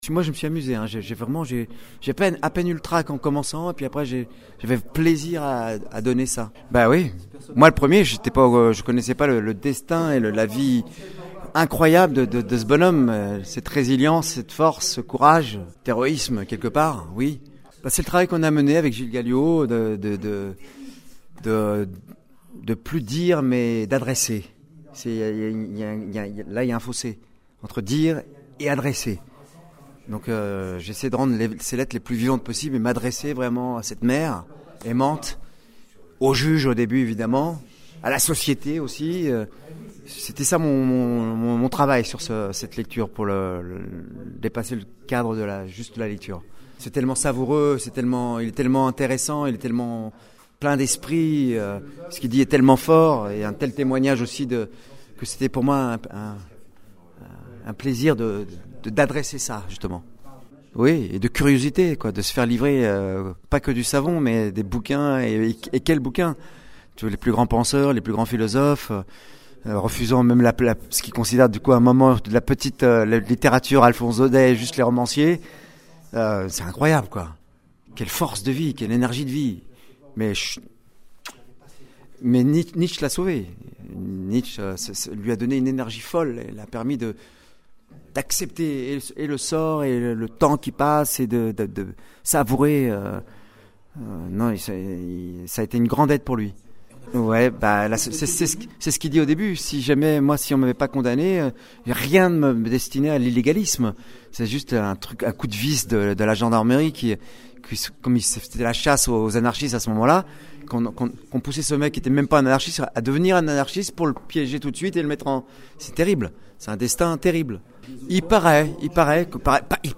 Thierry Frémont, comédien passionné par les destins hors du commun, a récemment partagé ses réflexions sur son dernier projet lors d’une interview pour La Radio du Cinéma.  Thierry Frémont , reconnu pour ses performances intenses et émouvantes, s’est plongé dans la vie fascinante et tumultueuse de Marius Jacob, un anarchiste au grand cœur et cambrioleur de génie, à travers la lecture de ses correspondances pour le festival de Grignan 2024.